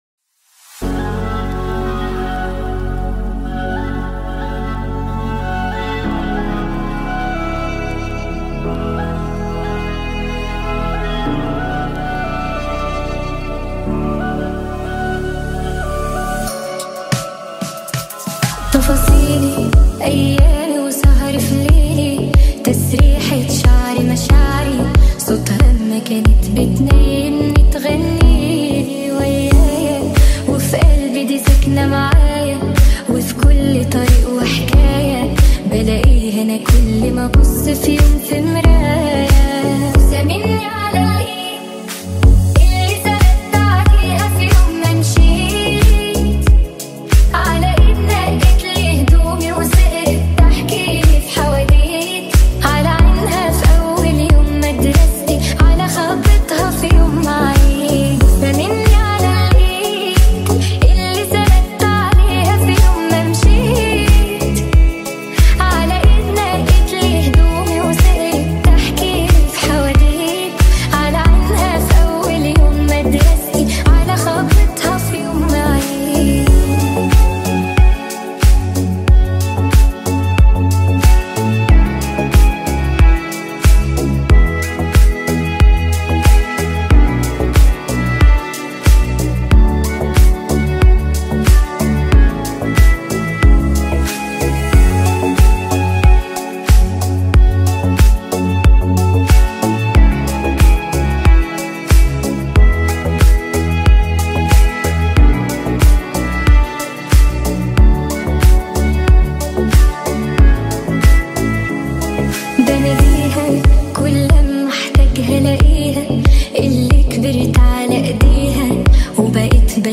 Турецкие песни, Ремиксы